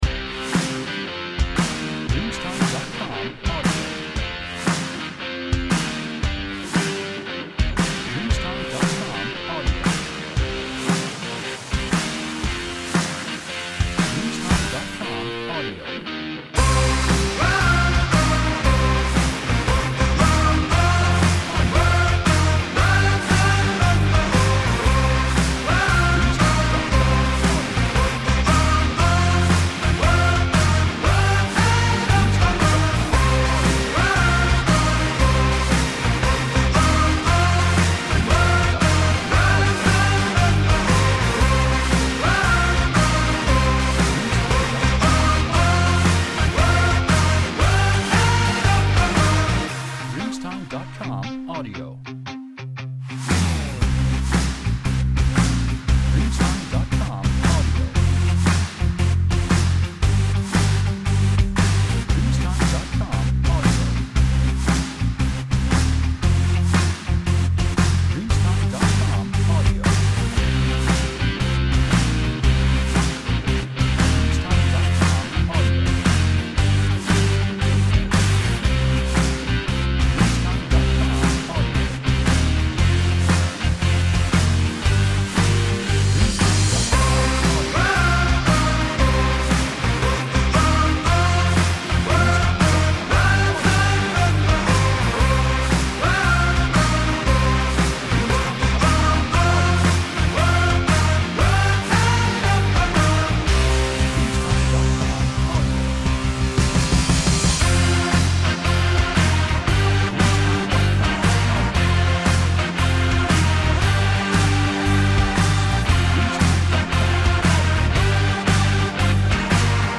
Energetic Indie Rock [Loop 1]